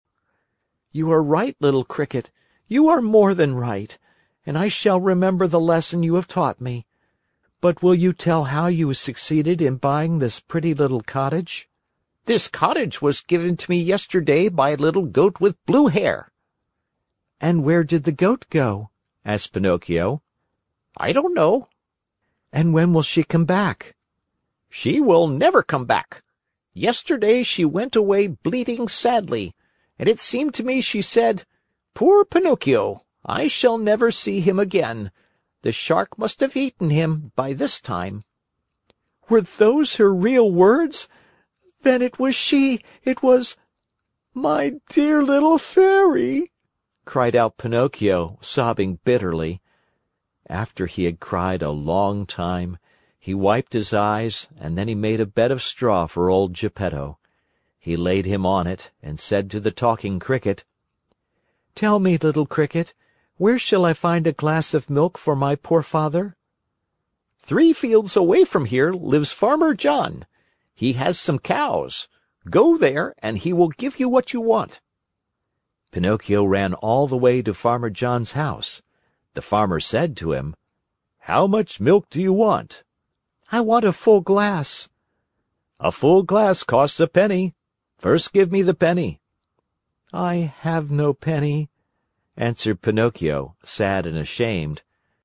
在线英语听力室木偶奇遇记 第160期:匹诺曹梦想成真(6)的听力文件下载,《木偶奇遇记》是双语童话故事的有声读物，包含中英字幕以及英语听力MP3,是听故事学英语的极好素材。